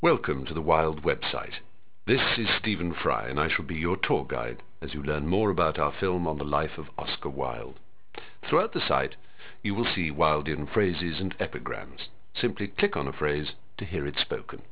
Un Benvenuto dai produttori